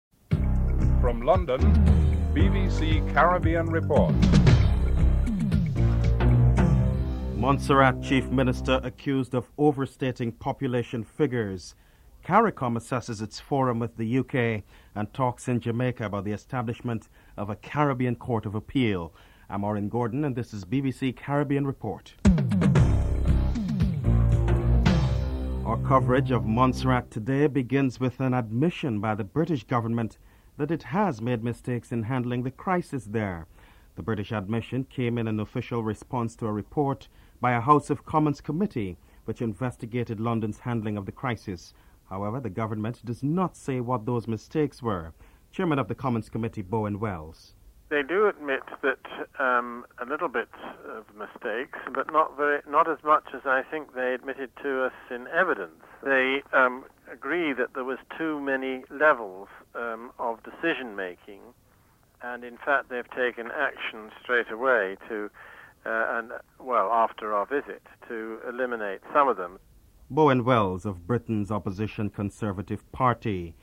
3. British Foreign Secretary Robin Cook sums up the first ever UK-CARICOM forum and Guyana's Foreign Minister Clement Rohee comments if CARICOM is really satisfied with the meeting (07:06-09:59)
Grenada's Prime Minister Keith Mitchell states that a task force will be set up to determine how this can be accomplished (12:41-14:37)